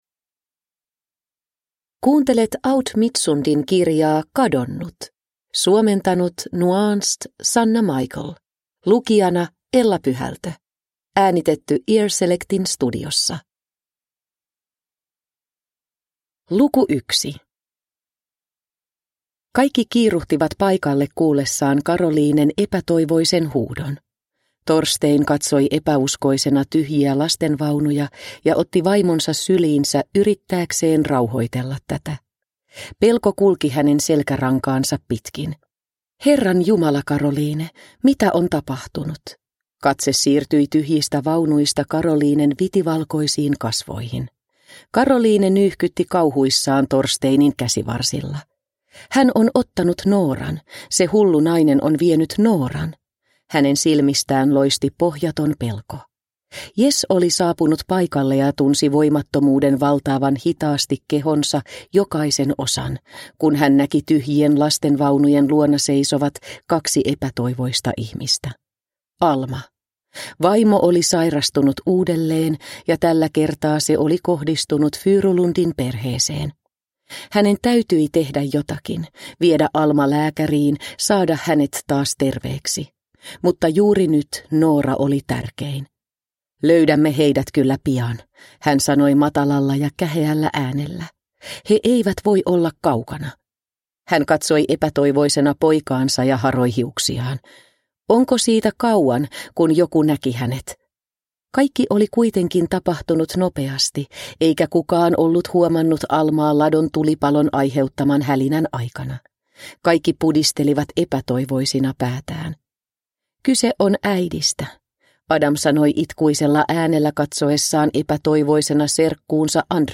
Kadonnut – Ljudbok – Laddas ner